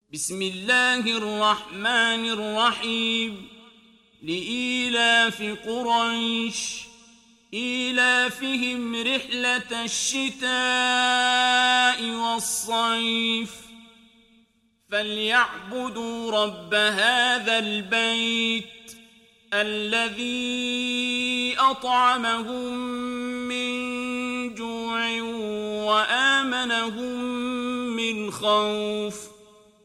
دانلود سوره قريش mp3 عبد الباسط عبد الصمد روایت حفص از عاصم, قرآن را دانلود کنید و گوش کن mp3 ، لینک مستقیم کامل